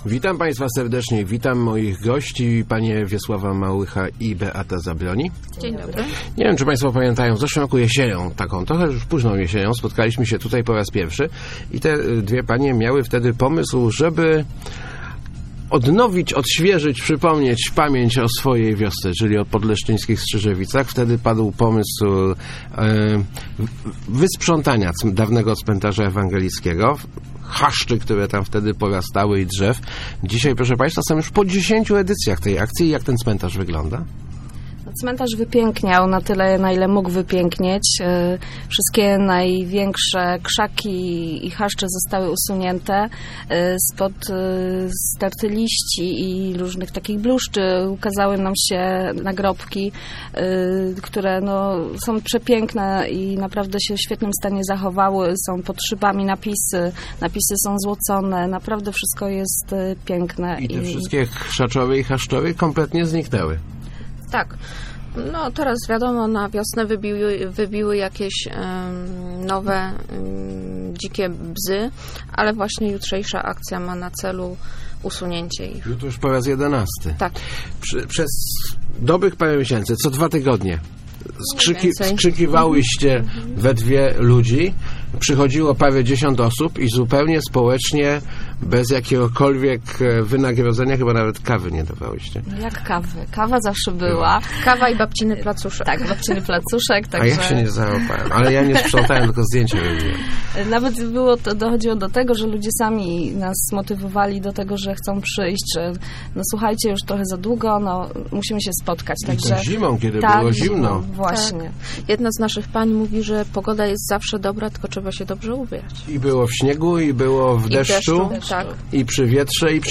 Start arrow Rozmowy Elki arrow Przywracanie pamięci w Strzyżewicach